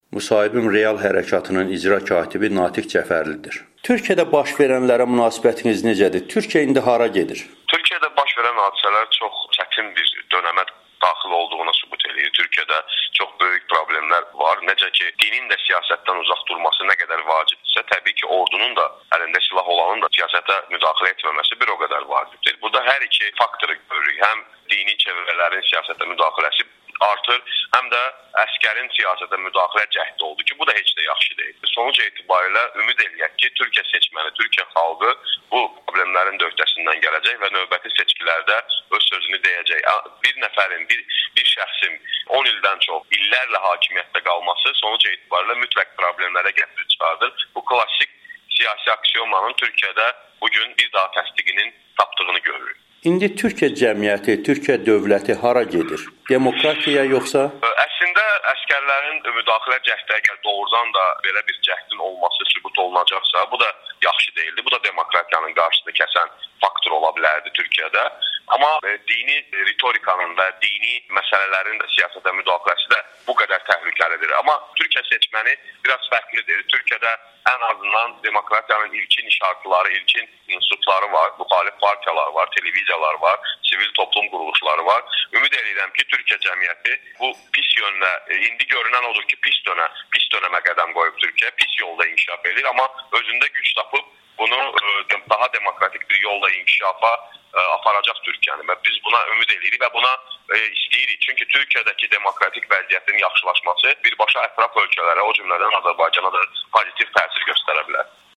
Azərbaycan siyasətçilərinin Türkiyə olayları ilə bağlı Amerikanın Səsinə müsahibələri